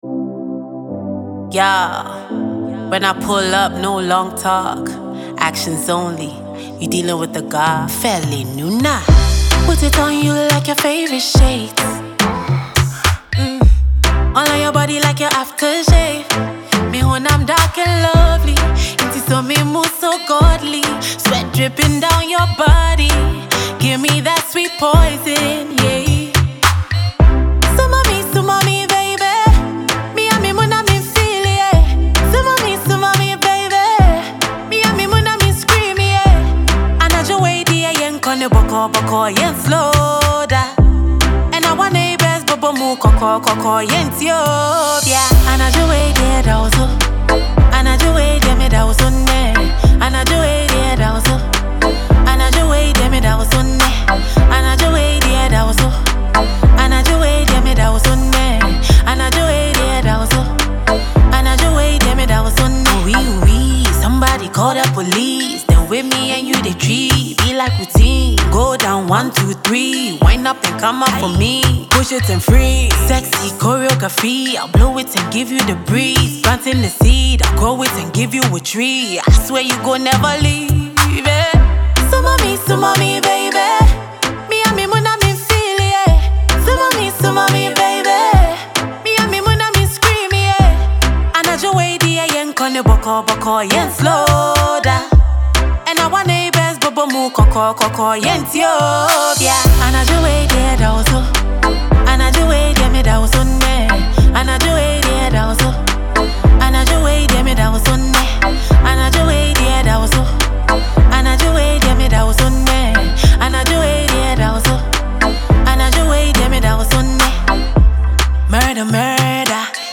a Ghanaian female artiste